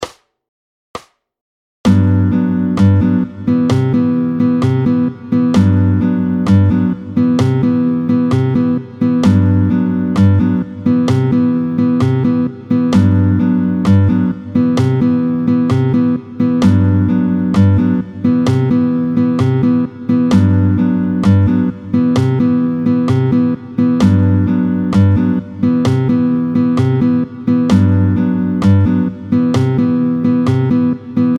30-04 Song for Baden. Vite, 2/2 tempo 130